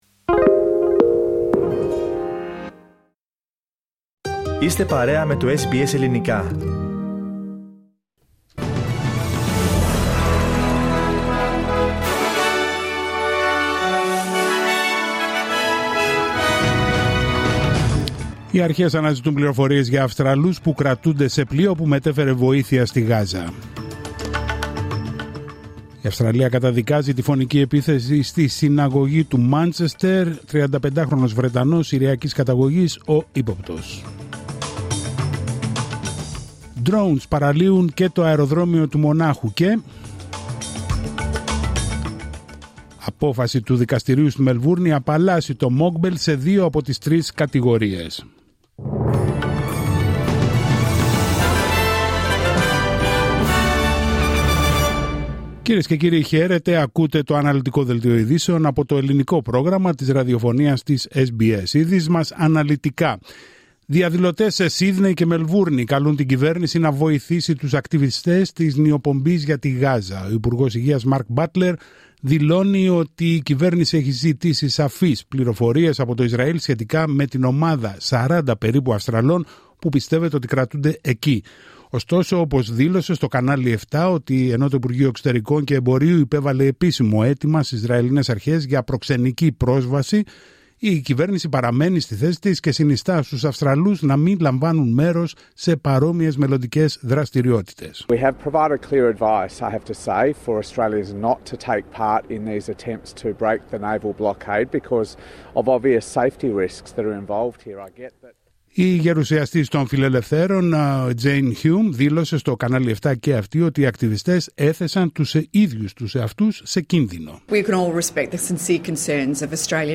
Δελτίο ειδήσεων Παρασκευή 3 Οκτωβρίου 2025